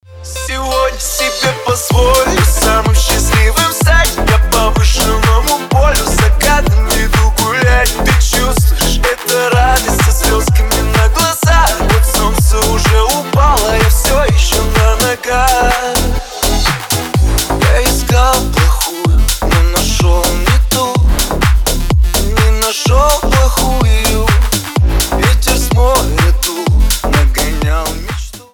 ремиксы
клубные
поп